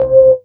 20ORGN02  -R.wav